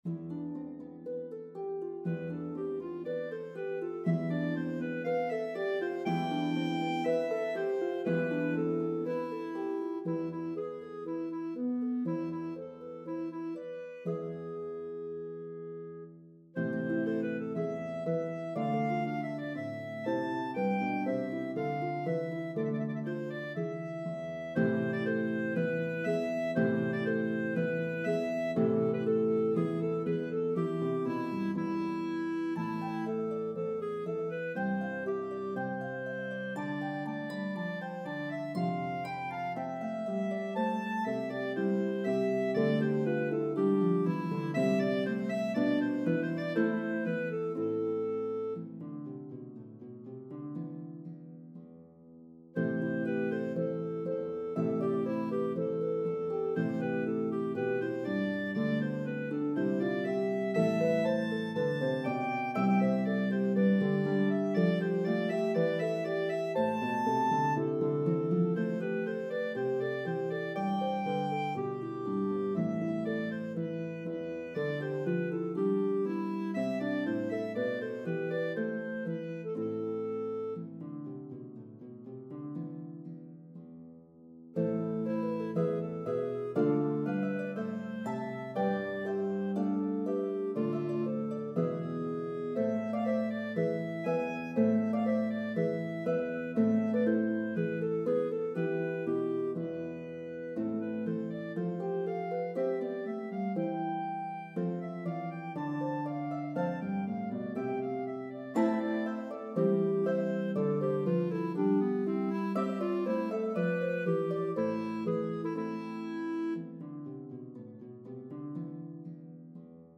Air